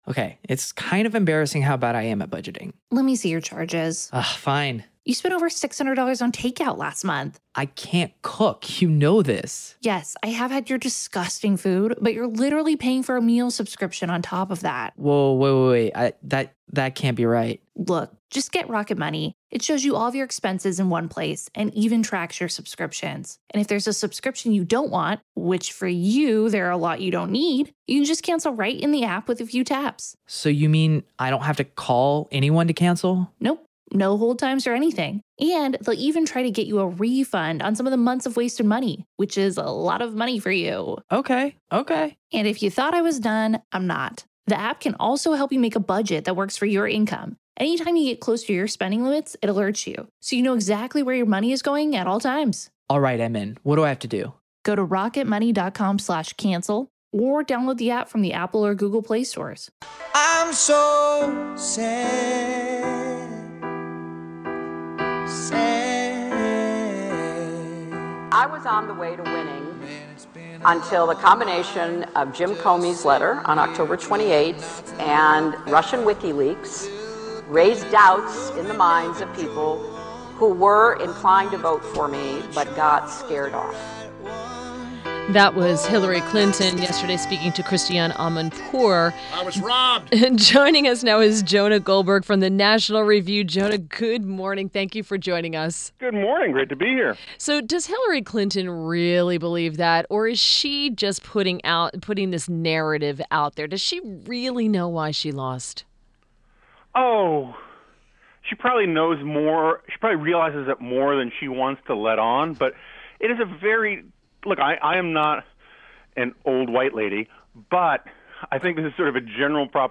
WMAL Interview - JONAH GOLDBERG - 05.03.17
INTERVIEW – JONAH GOLDBERG – columnist, Senior Editor at National Review and a Fellow at AEI – analyzed Hillary Clinton’s comments.